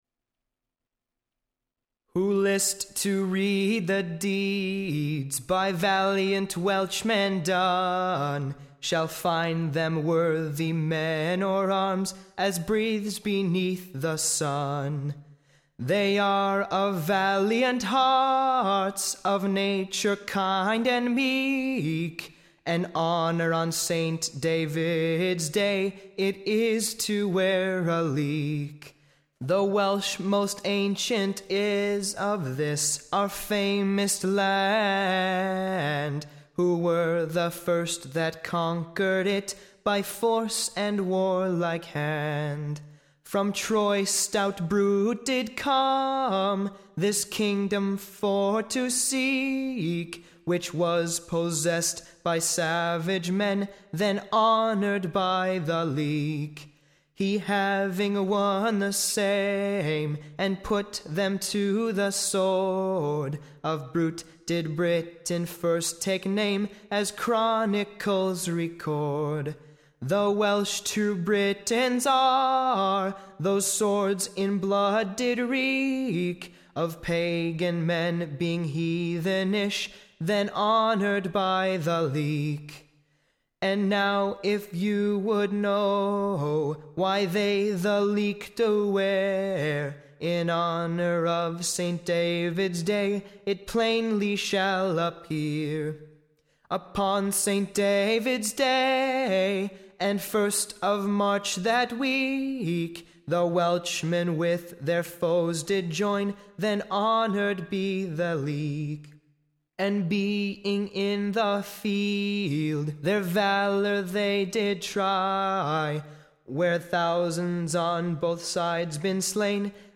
Recording Information Ballad Title The Praise of Saint Dauids day.
Tune Imprint To the tune of When this Old Cap was new.